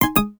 VolumeUp.wav